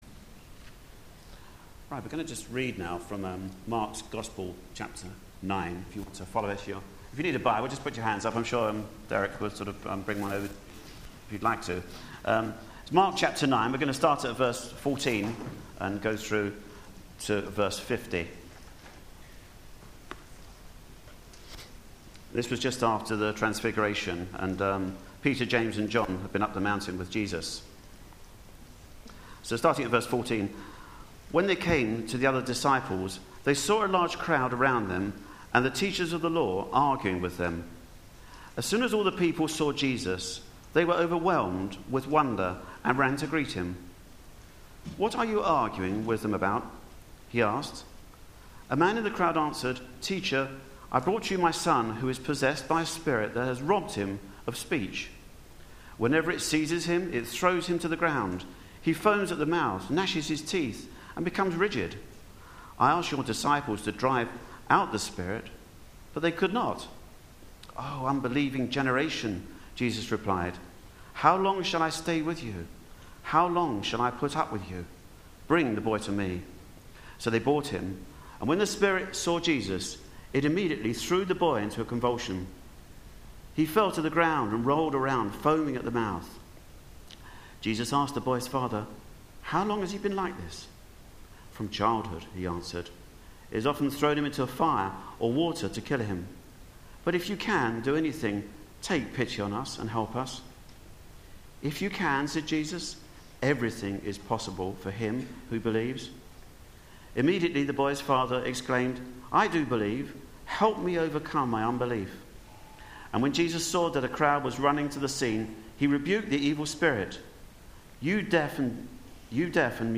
Media for Sunday Service
Sermon